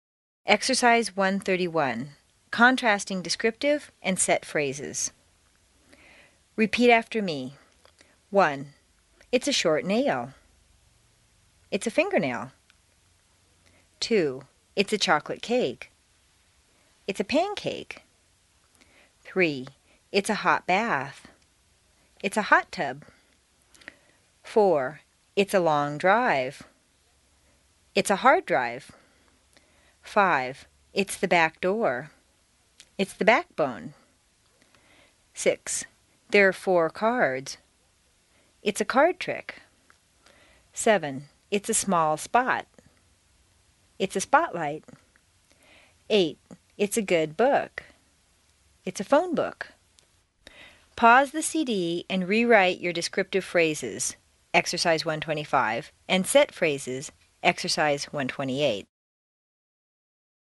美语口语发音训练 第一册44